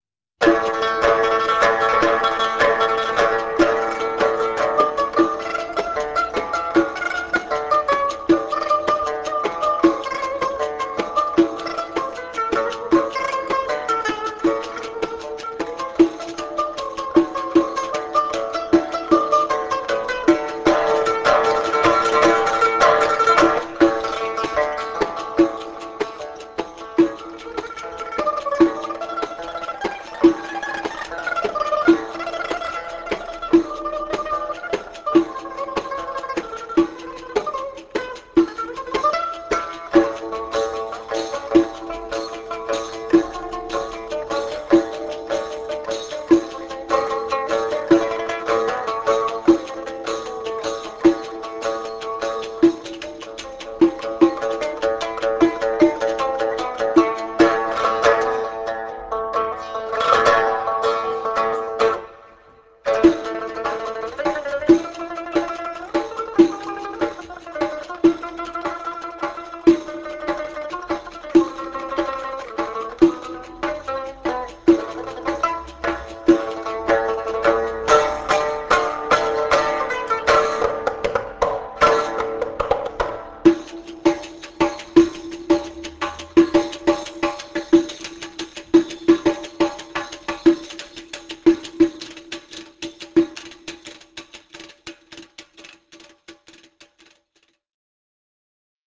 deff, zarb, tar, dumbek